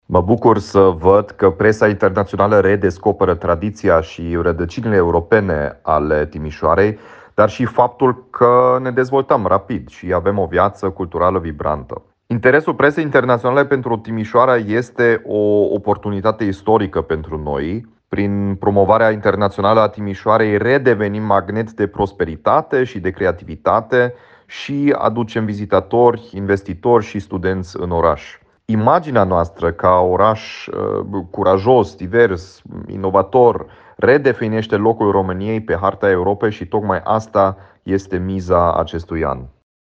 Atragerea de vizitatori dar și de investitori este de altfel miza acestui an – spune primarul Dominic Fritz.